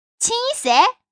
Index of /hunan_feature4/update/12624/res/sfx/changsha_woman/